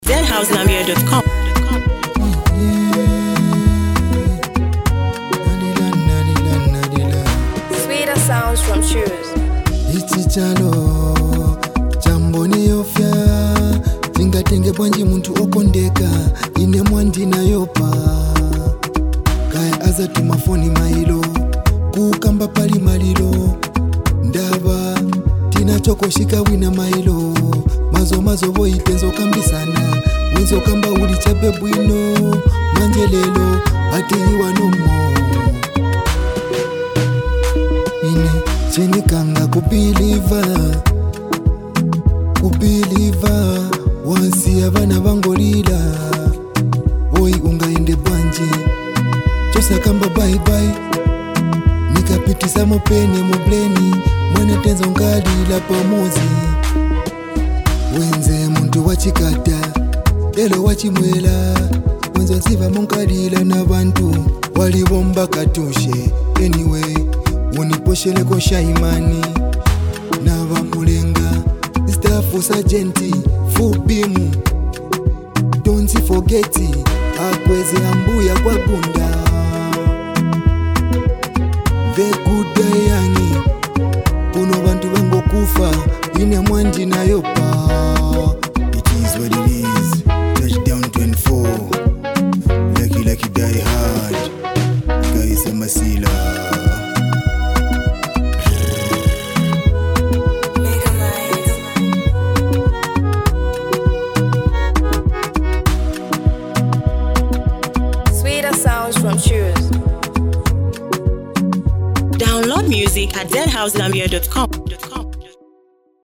a powerful and emotional track